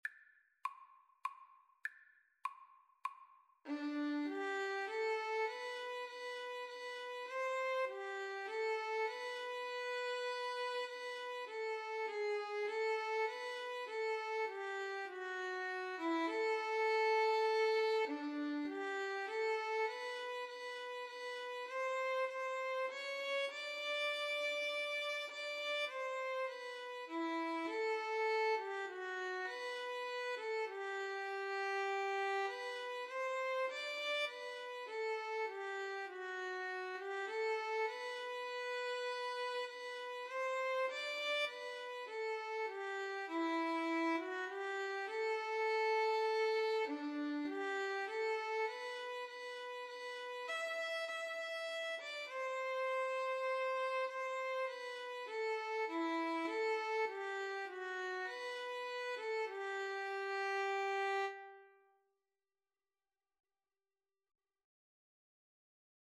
3/4 (View more 3/4 Music)
Classical (View more Classical Violin Duet Music)